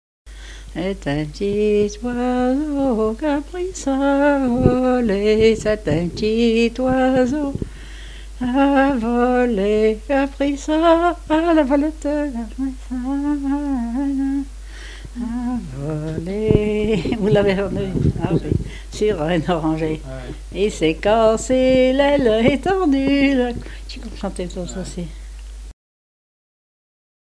Enquête Arexcpo en Vendée
Chanson
Pièce musicale inédite